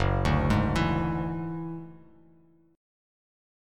Gb7sus2#5 chord